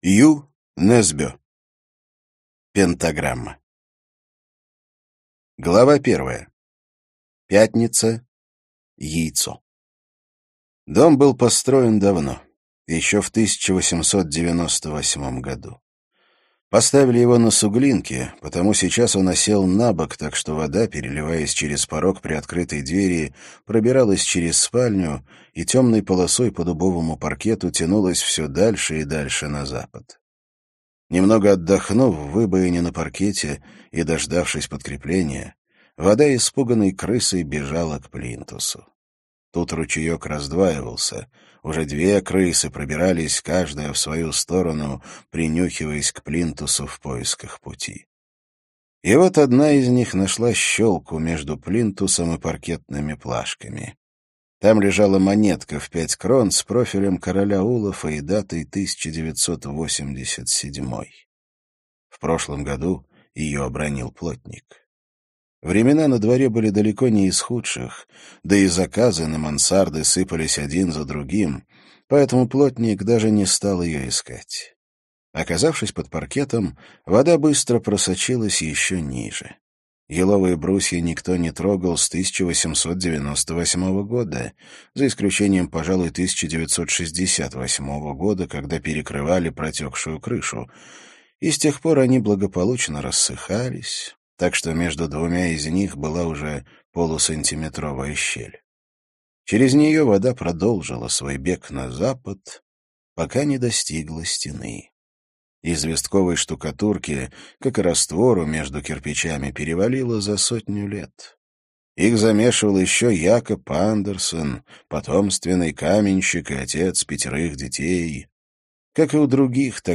Аудиокнига Пентаграмма - купить, скачать и слушать онлайн | КнигоПоиск